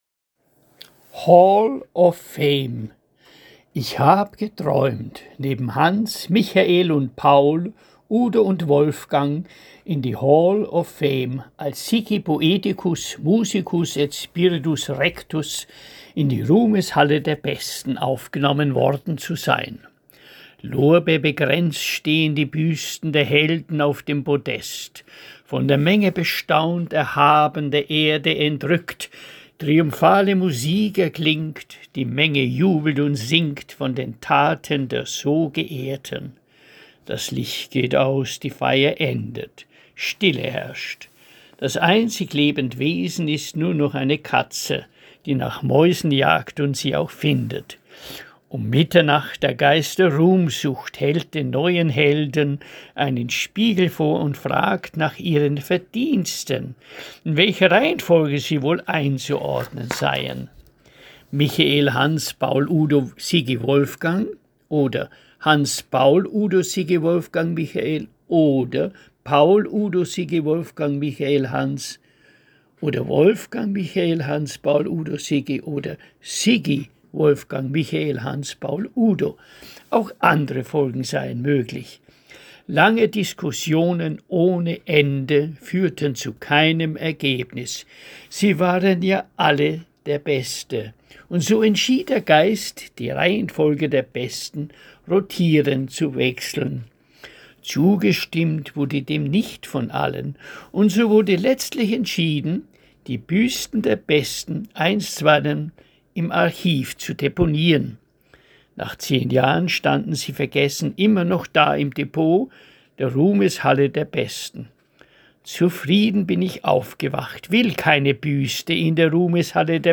Lesung eigener Gedichte